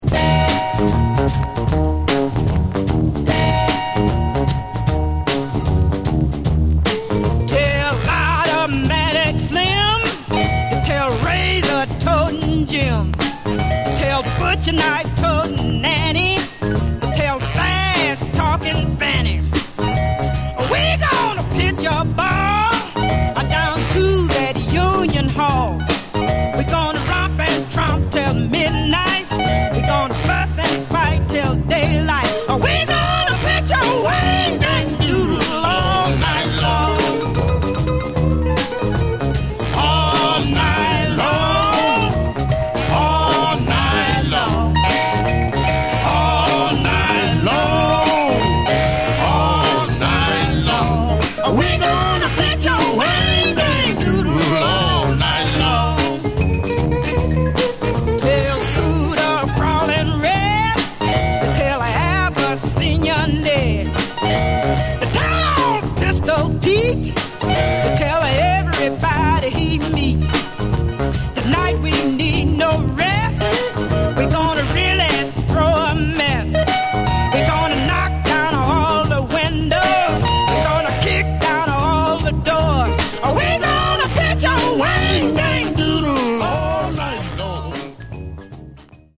Genre: Blues/Blues Rock